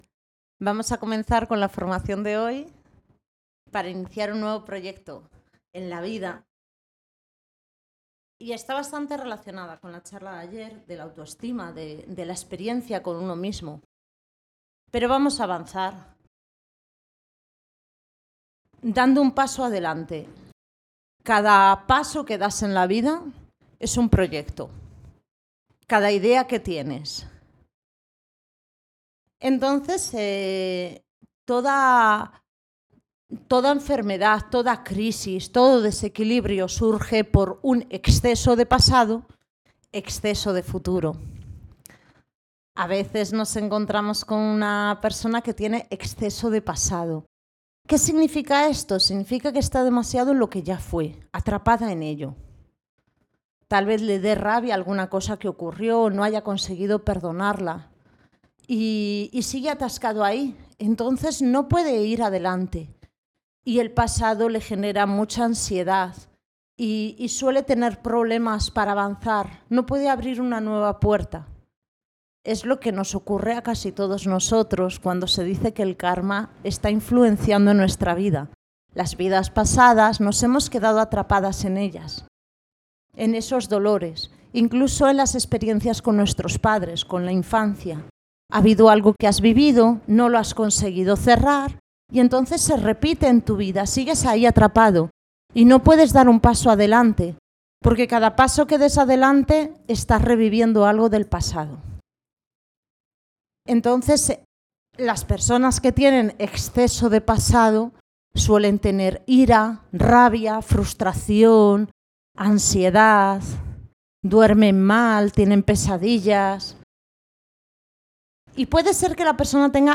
Formación, charla Iniciando un nuevo proyecto